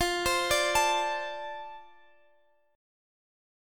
Listen to F6 strummed